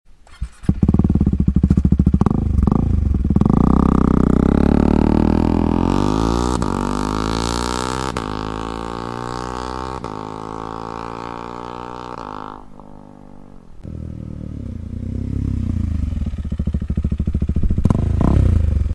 Pure sound KTM duke 390 sound effects free download
Pure sound KTM duke 390 full exhaust not catalyst